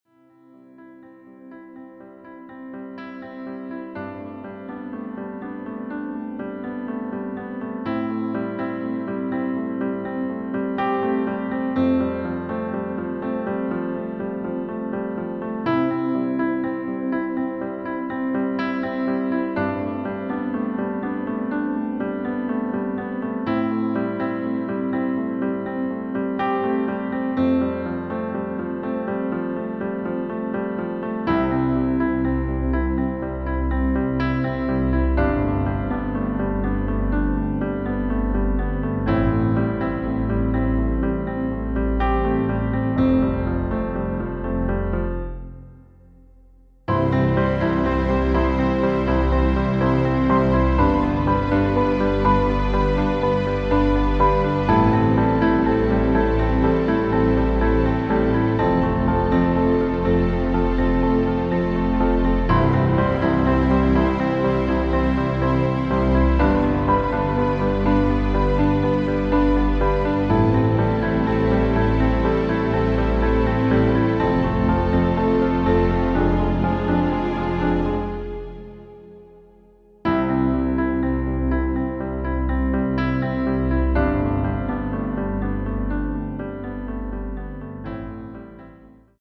• Tonart: Bb Dur, C Dur, D Dur, E Dur
• Das Instrumental beinhaltet NICHT die Leadstimme
Klavier / Streicher